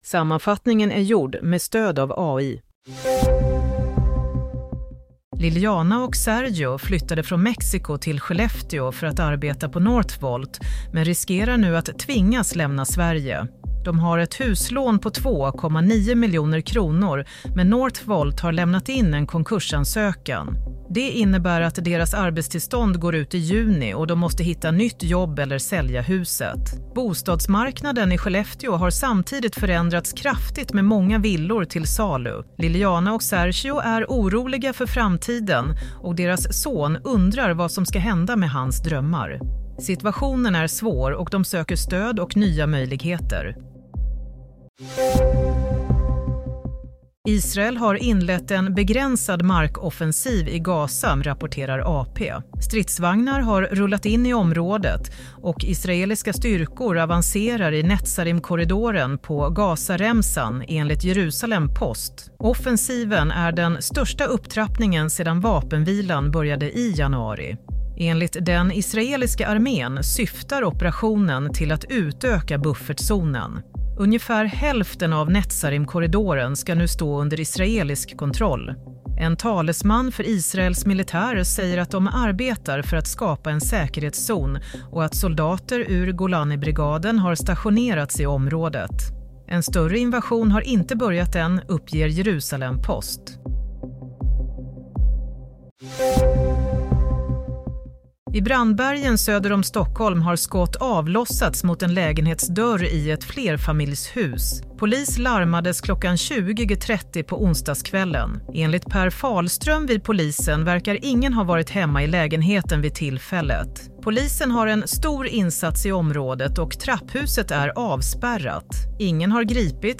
Play - Nyhetssammanfattning 20 mars 07.00